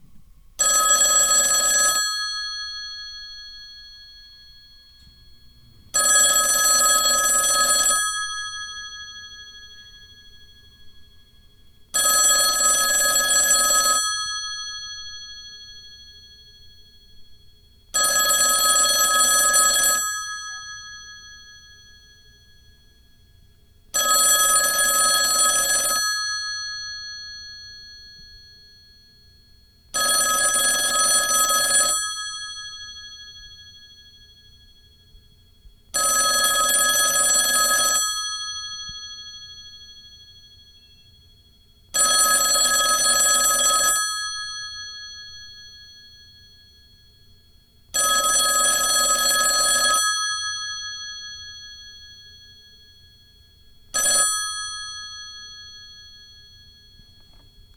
Ringetone Klassisk ringring